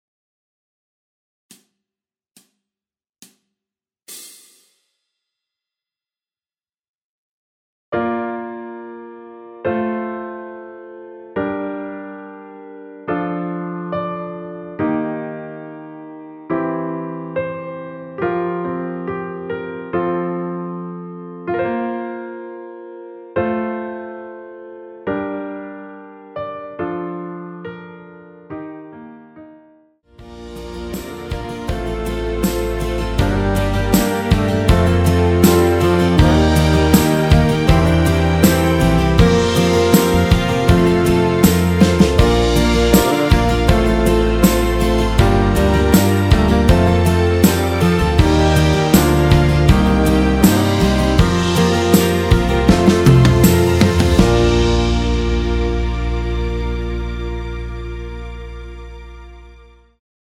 노래가 바로 시작하는곡이라 카운트 넣어 놓았으며
그리고 엔딩이 너무 길고 페이드 아웃이라 라랄라 반복 2번으로 하고 엔딩을 만들었습니다.
Bb
앞부분30초, 뒷부분30초씩 편집해서 올려 드리고 있습니다.
중간에 음이 끈어지고 다시 나오는 이유는